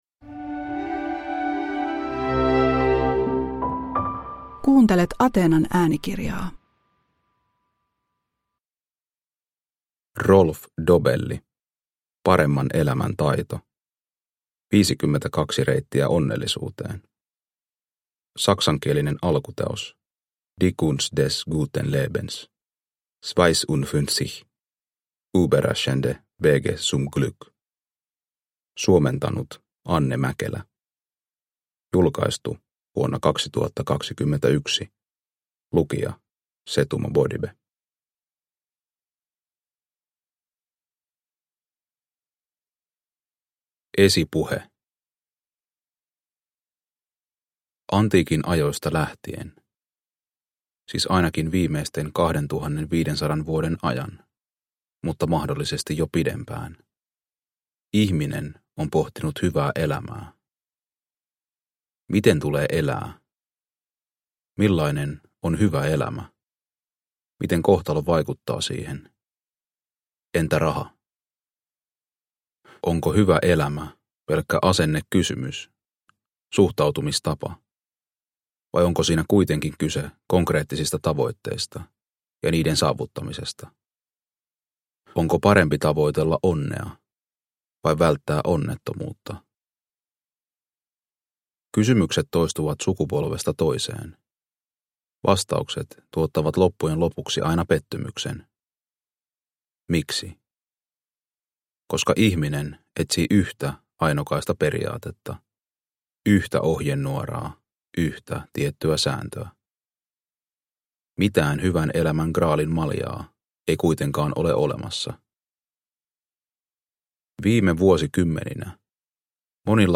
Paremman elämän taito – Ljudbok – Laddas ner